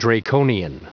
Prononciation du mot draconian en anglais (fichier audio)
Prononciation du mot : draconian